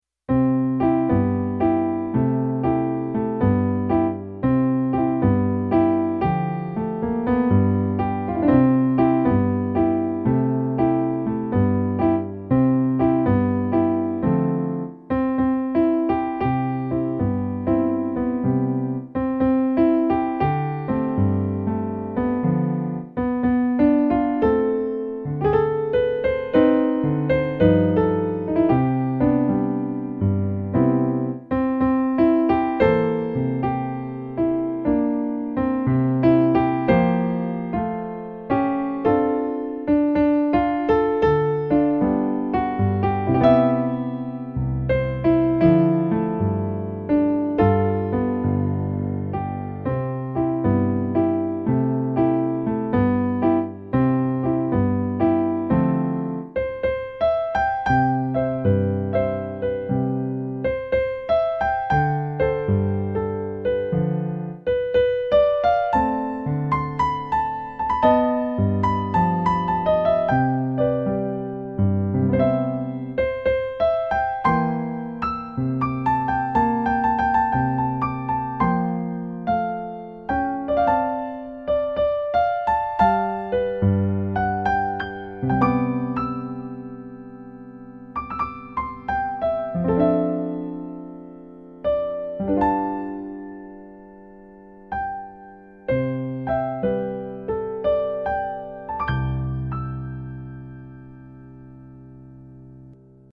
jazz samba remix